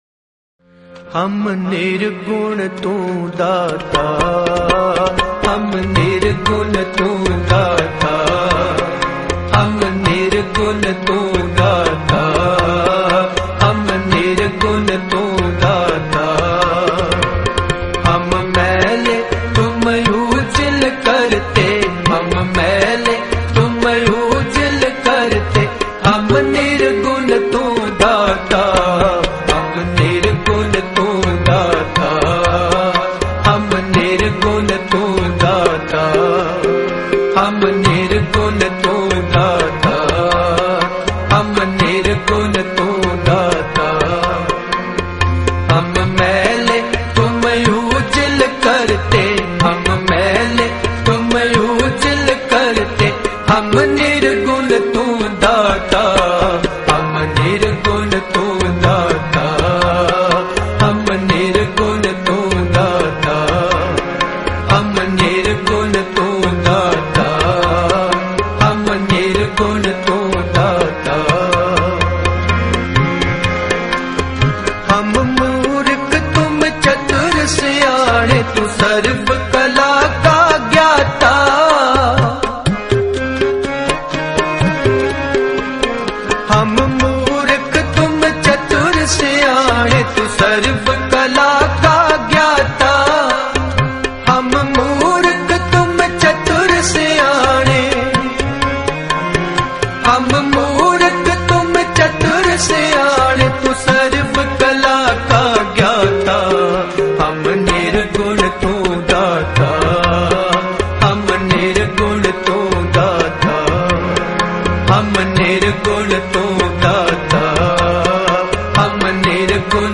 Gurbani Kirtan